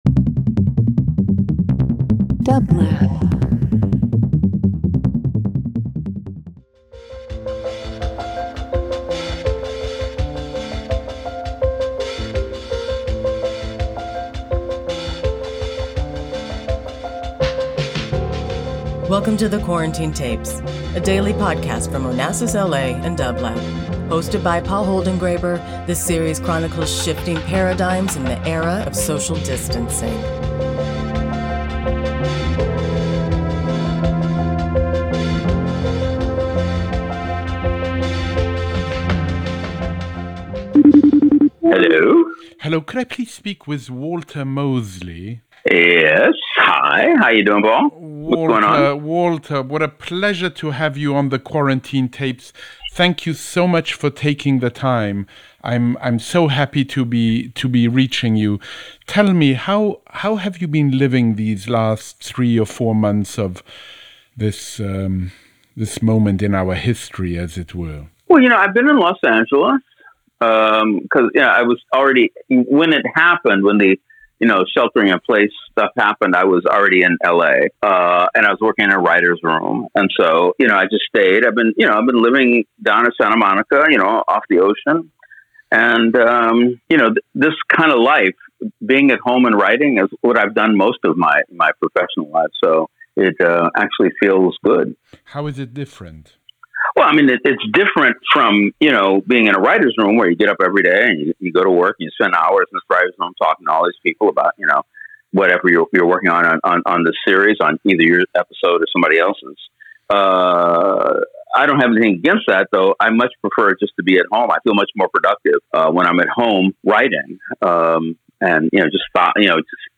Each day, Paul calls a guest for a brief discussion about how they are experiencing the global pandemic.
Paul Holdengräber Walter Mosley The Quarantine Tapes 07.23.20 Interview Talk Show The Quarantine Tapes : A week-day program from Onassis LA and dublab.